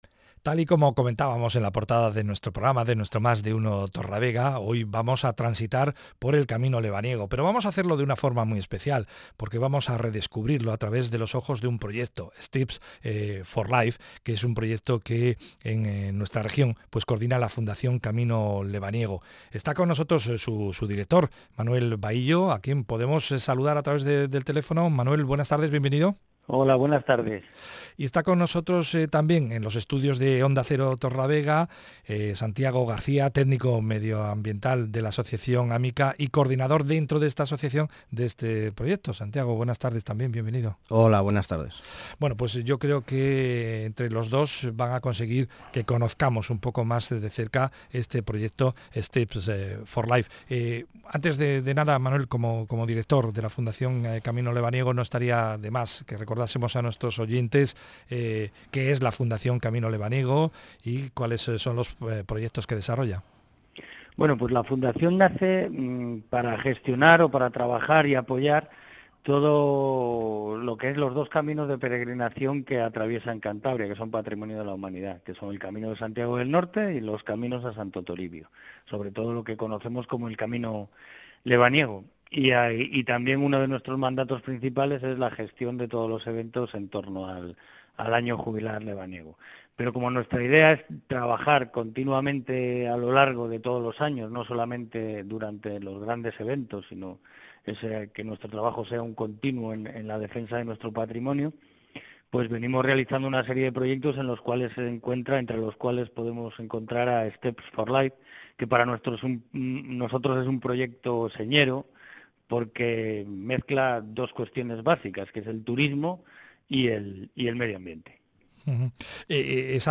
Entrevista en Onda Cero Torrelavega. Proyecto Steps for LIFE